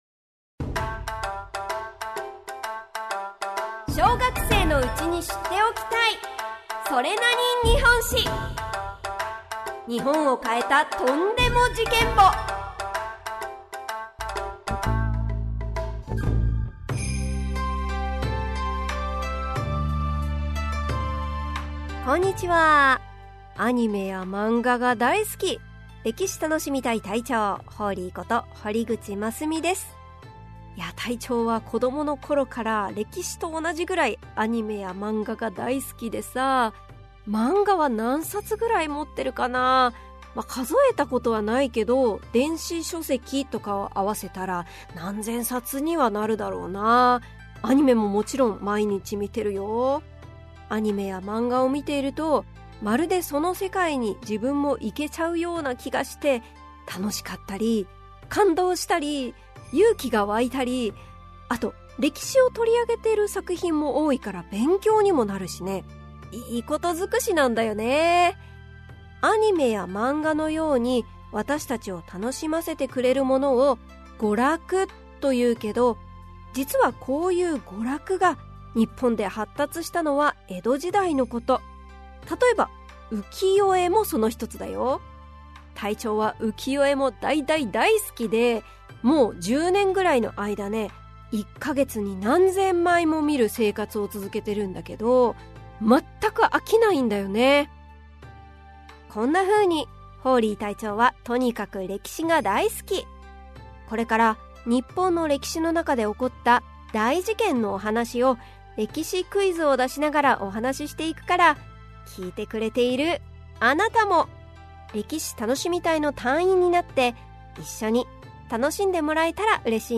[オーディオブック] それなに？日本史 Vol.28 〜蔦屋重三郎と歌麿・写楽の浮世絵〜